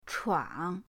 chuang3.mp3